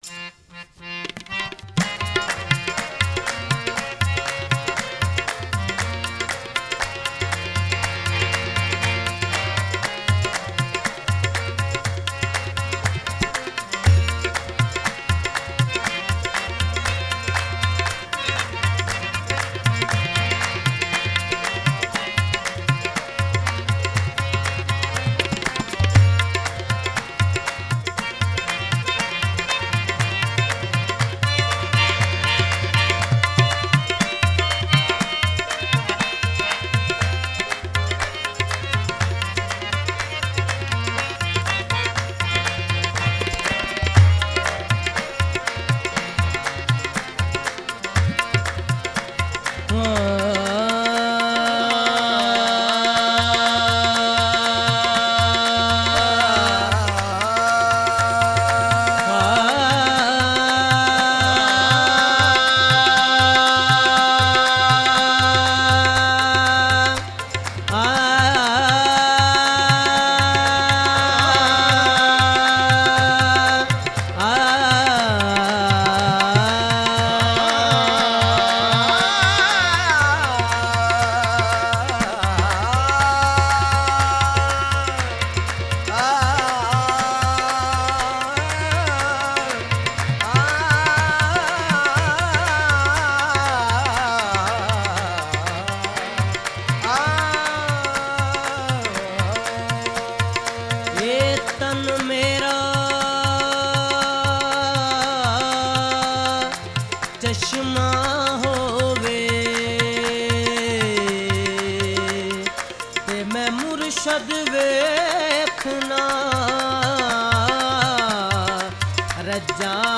Qawwali Sufi
Sufiana Kalam